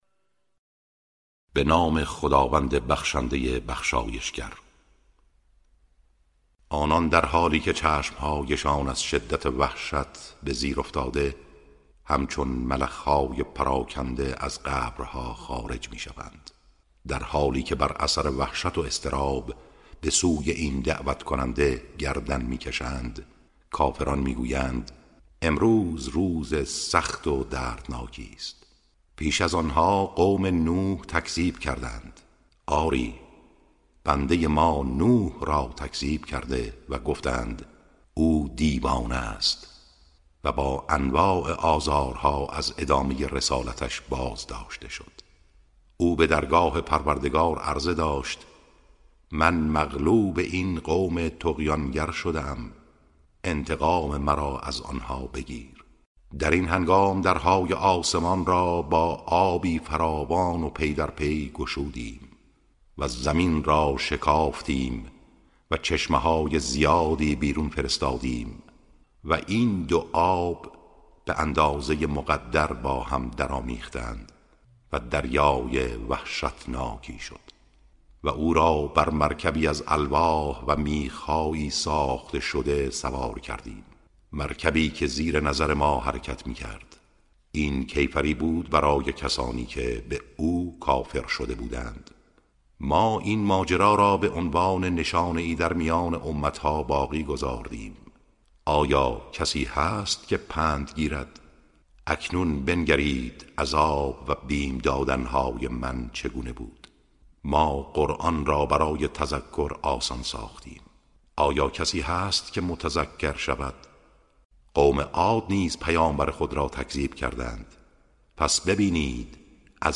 ترجمه سوره(قمر)